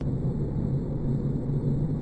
spaceambience1.mp3